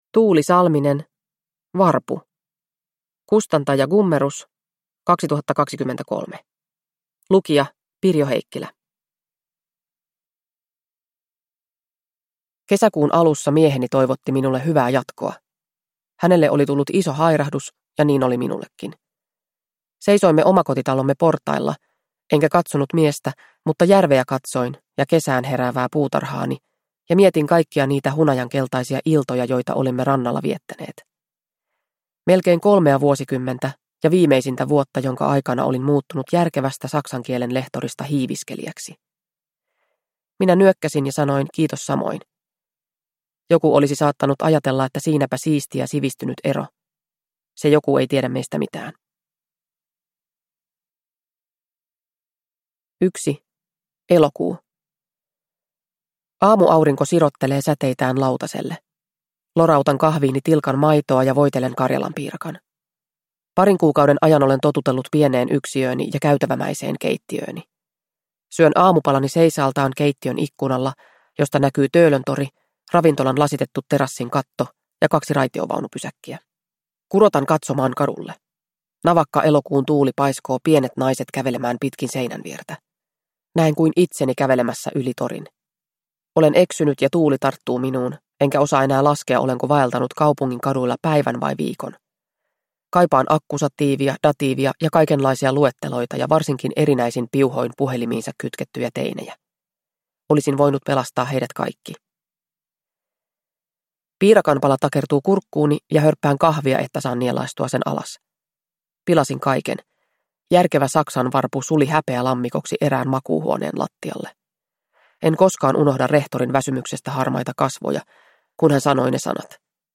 Varpu – Ljudbok – Laddas ner